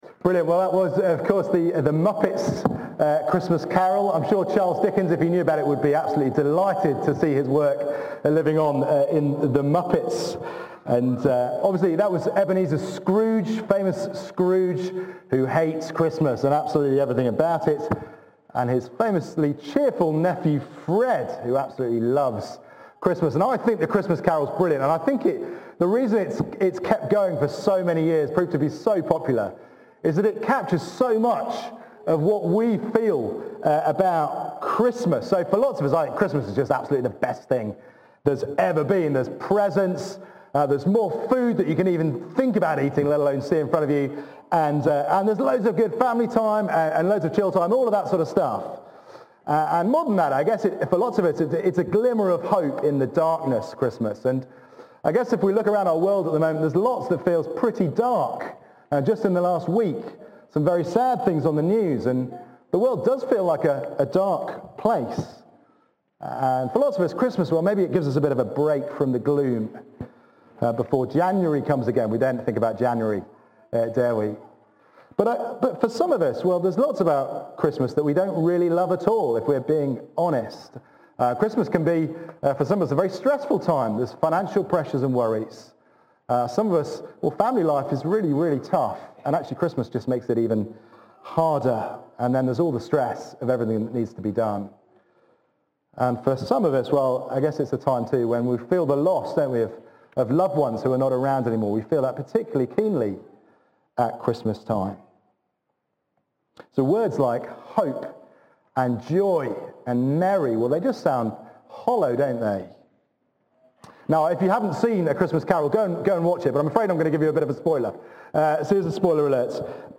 Matthew 2:1-12 – Carols for All Service